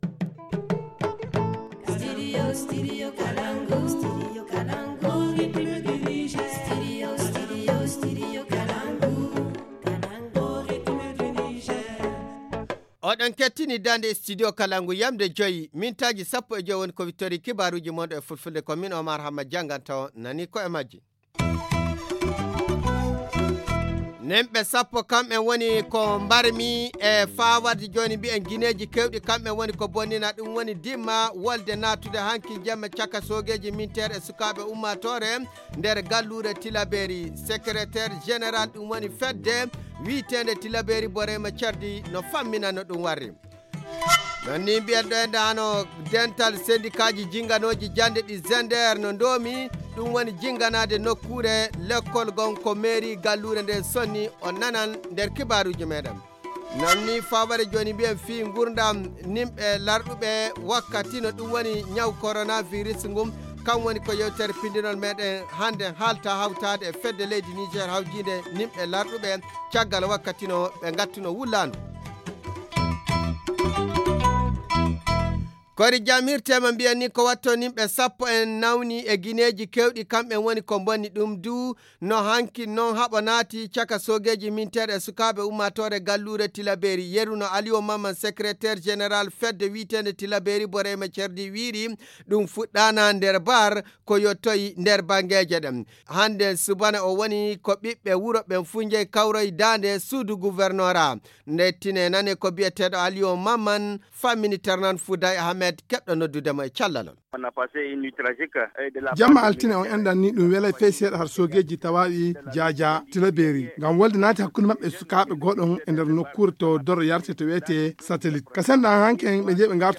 Le journal du 03 juin 2020 - Studio Kalangou - Au rythme du Niger